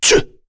Dakaris-Vox_Attack2_kr.wav